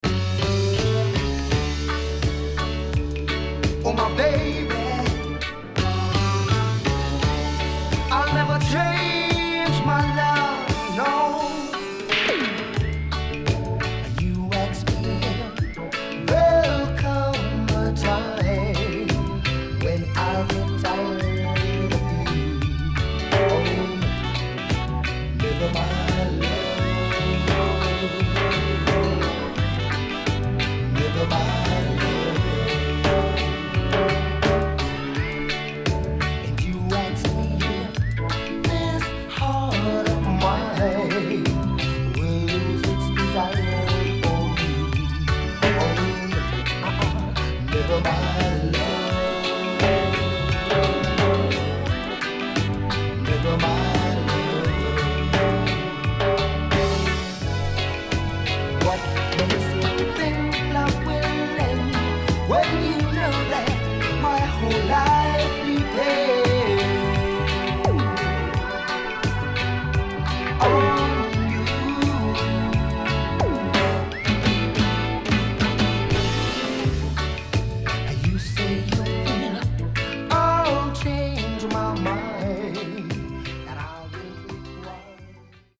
HOME > REISSUE USED [DANCEHALL]